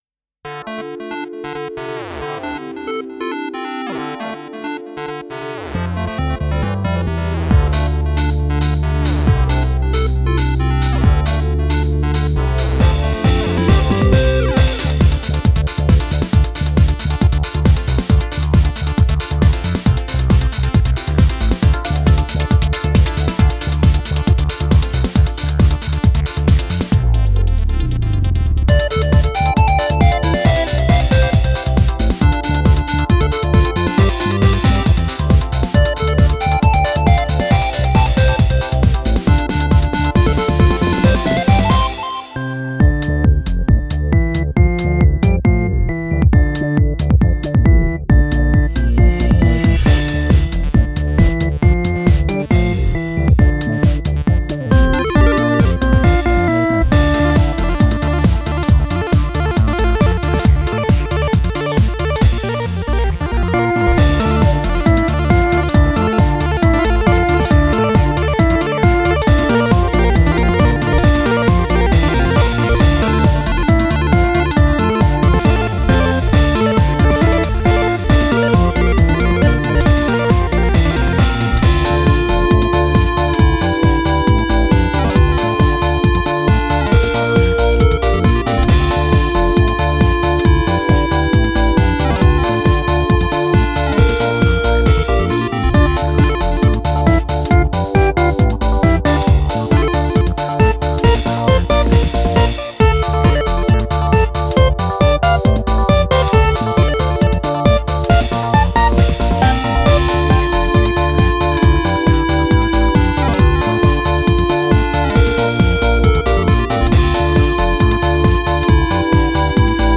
Synths meet silicon chips in Dar es Salaam